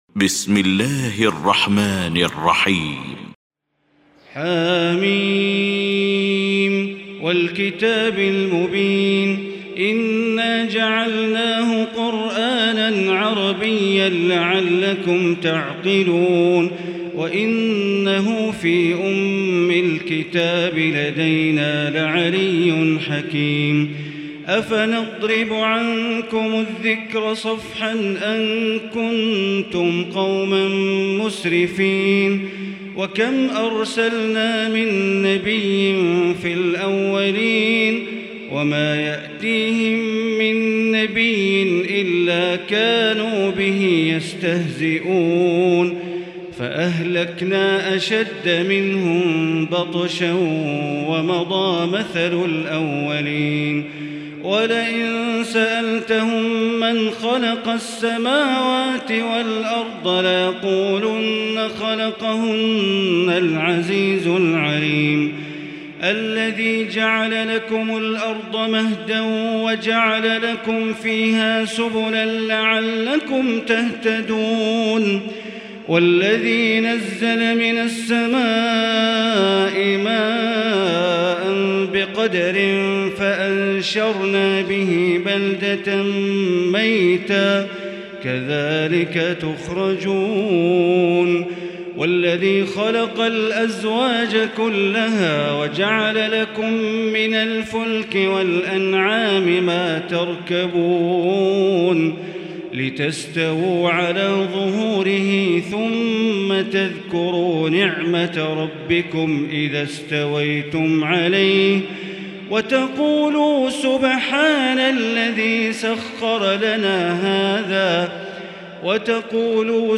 المكان: المسجد الحرام الشيخ: معالي الشيخ أ.د. بندر بليلة معالي الشيخ أ.د. بندر بليلة الزخرف